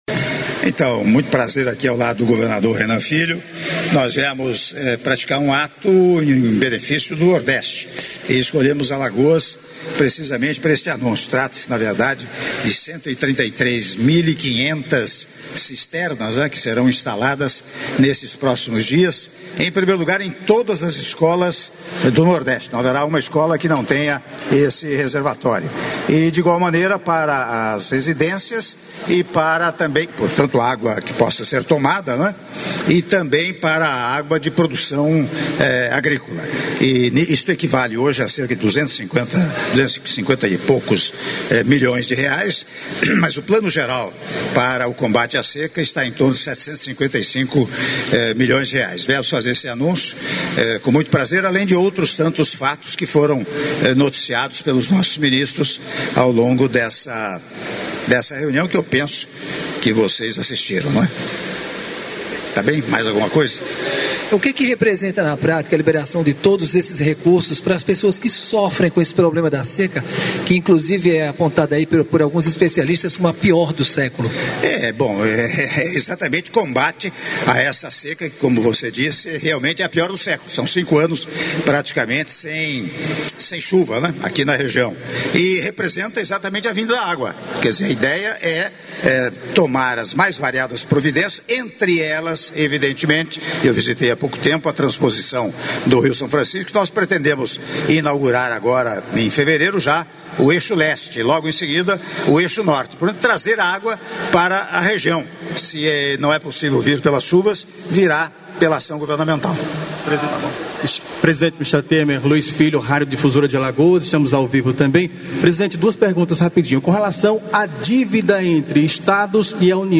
Entrevista coletiva concedida pelo presidente da República, Michel Temer, após cerimônia de anúncio de investimentos em ações para redução dos efeitos da seca e acesso à água - (03min52s) - Maceió/AL